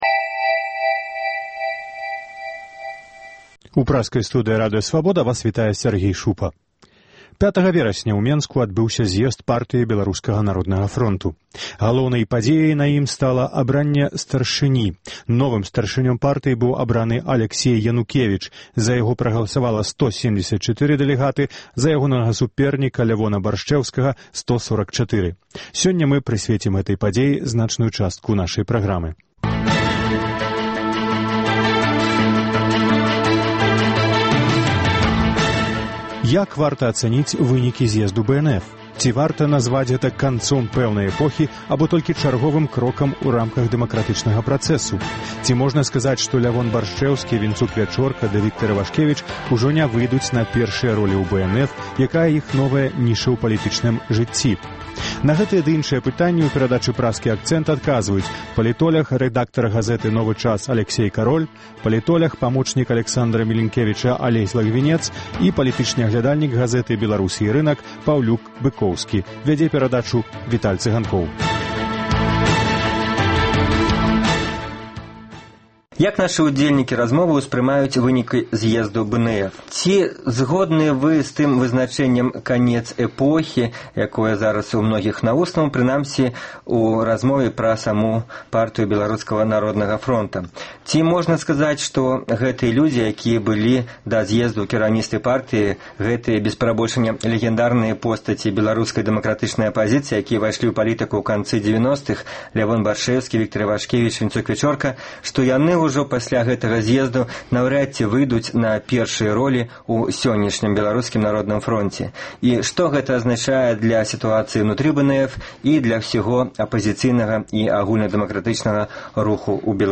Як вынікі зьезду паўплываюць на вылучэньне кандыдатаў у прэзыдэнты Беларусі? На гэтыя ды іншыя пытаньні адказваюць палітоляг